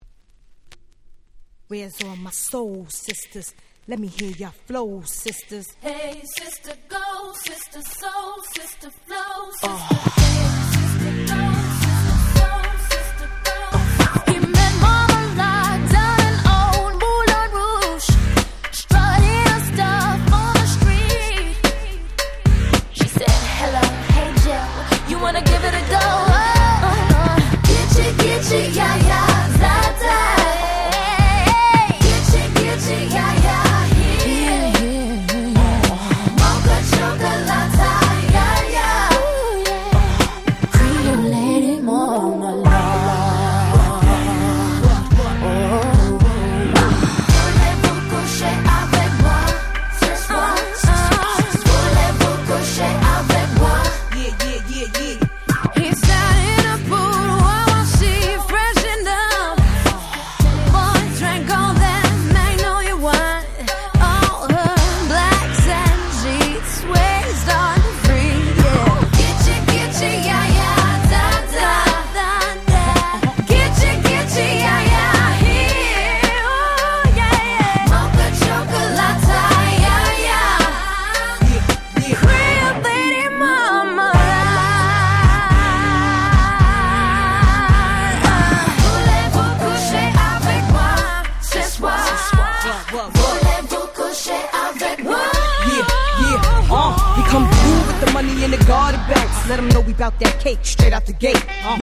01' Super Hit R&B !!